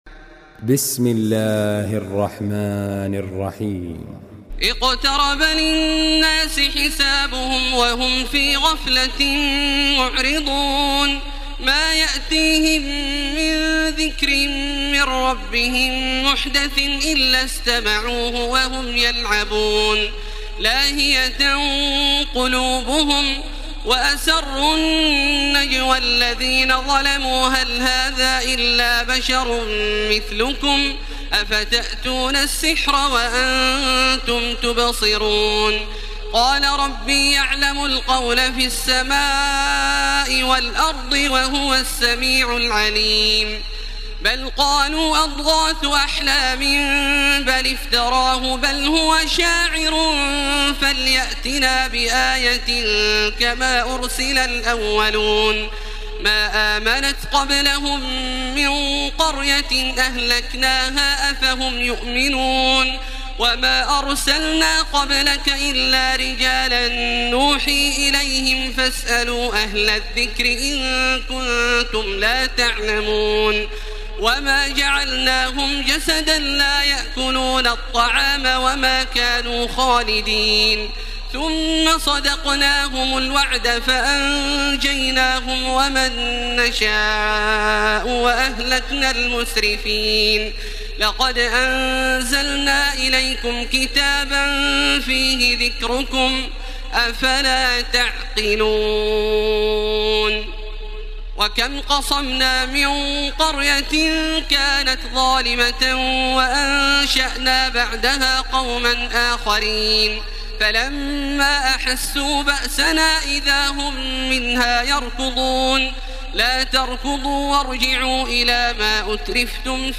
تراويح الليلة السادسة عشر رمضان 1434هـ سورة الأنبياء كاملة Taraweeh 16 st night Ramadan 1434H from Surah Al-Anbiyaa > تراويح الحرم المكي عام 1434 🕋 > التراويح - تلاوات الحرمين